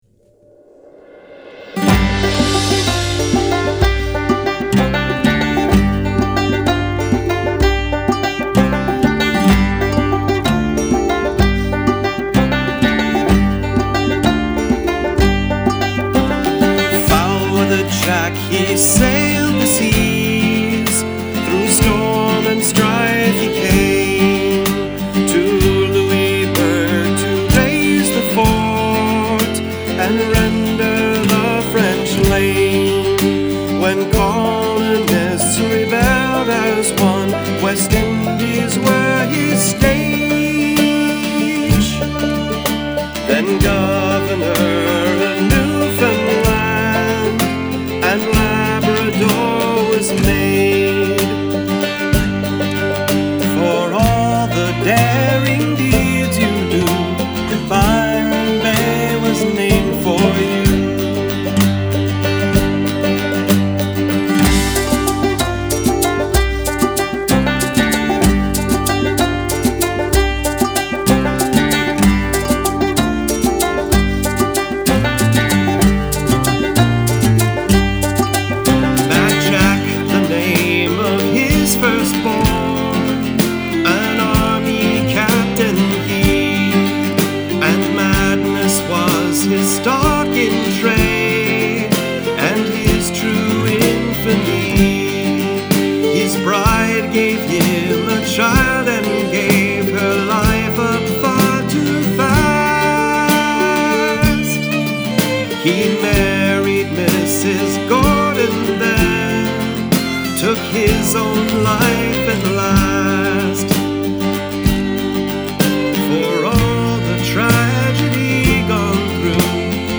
Violins
I love a historical ballard